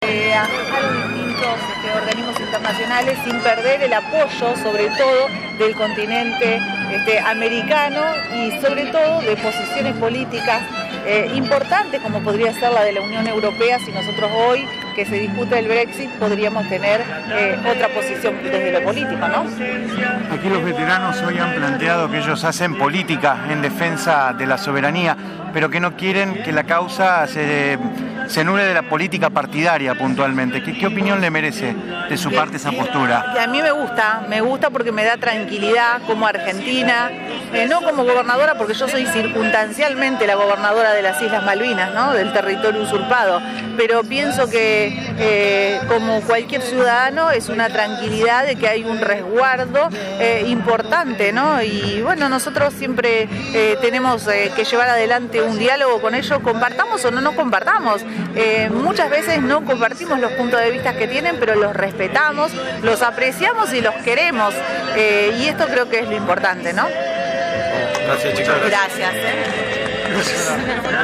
Mart 02/04/19.-La gobernadora de la provincia estuvo presente en la Vigilia por Malvinas y en dialogo con los medios se refirió al proyecto de Ley Malvinas que espera se apruebe en el congreso nacional. También se refirió a la postura de los veteranos en cuanto a no participar en política partidaria.